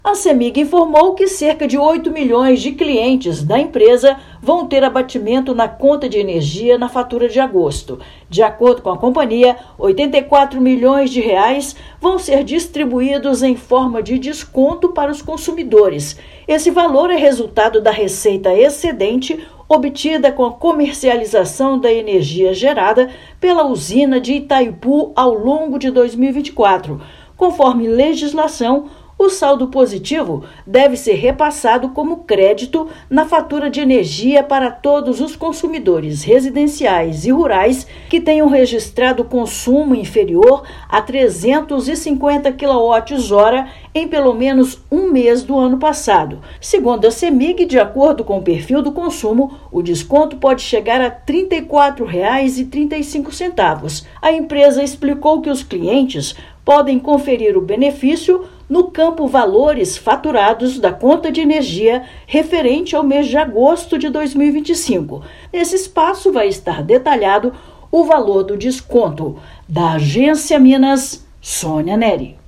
[RÁDIO] Clientes da Cemig receberão R$ 84 milhões em descontos na conta de energia em agosto
Valor é referente à receita excedente obtida com a comercialização da energia da Usina de Itaipu no mercado regulado em 2024. Ouça matéria de rádio.